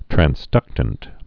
(trăns-dŭktənt, trănz-)